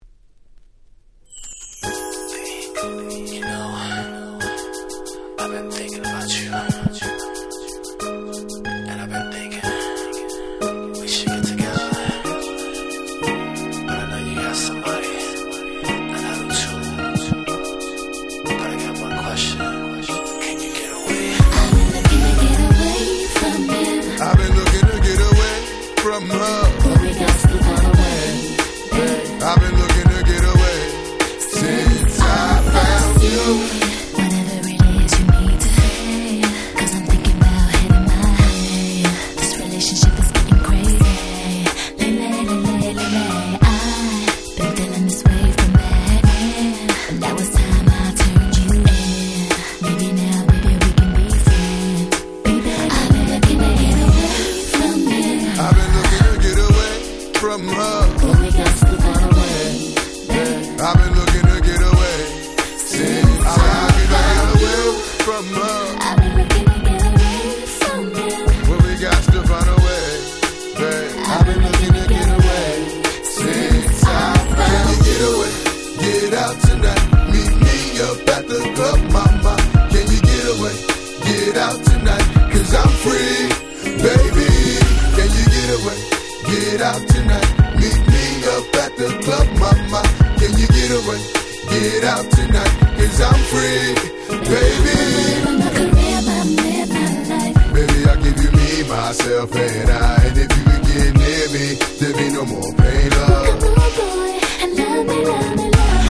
01' Smash Hit R&B !!